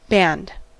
Ääntäminen
US : IPA : [ˈbænd]